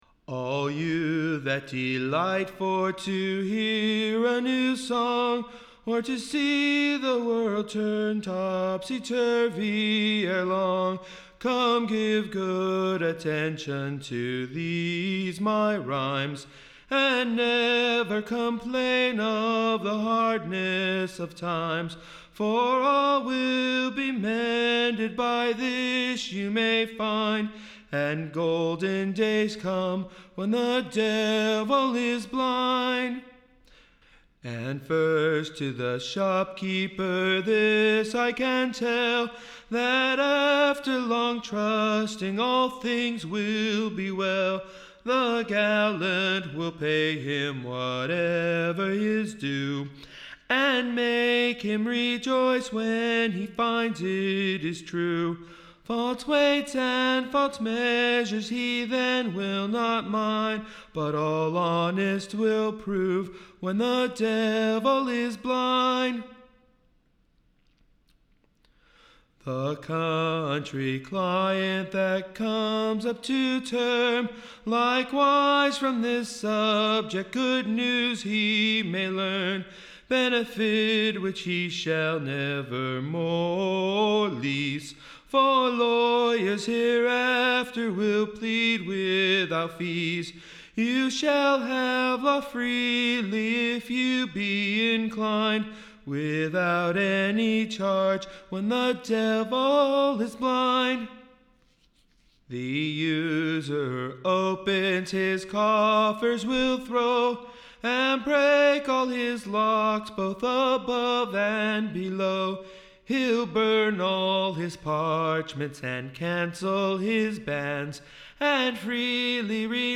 Recording Information Ballad Title Poor Robin's Prophesie, or, The merry Conceited Fortune-teller.